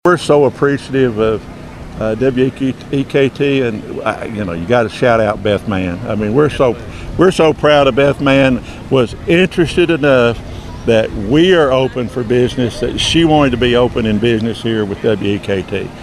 Mayor Green told WEKT News during the recent Todd County Harvest Festival there is an exciting forward momentum in Elkton and Todd County.